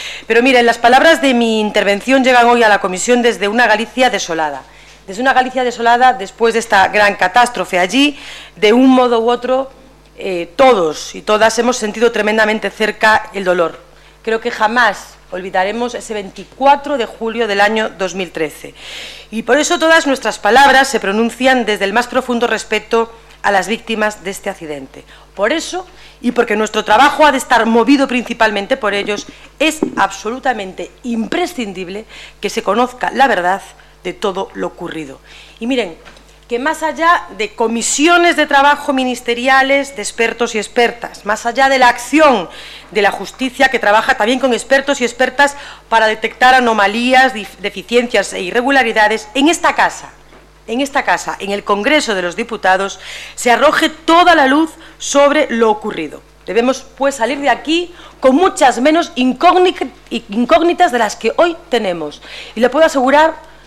Comisión de Fomento.